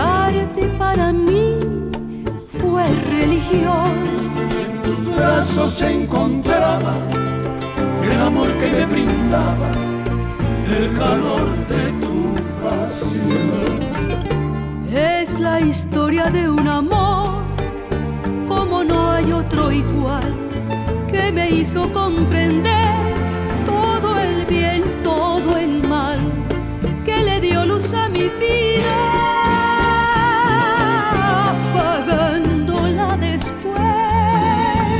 # Baladas y Boleros